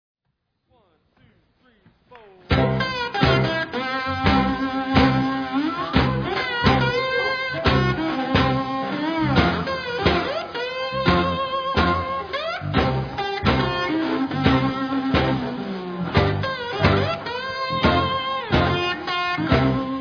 Southern (jižanský) rock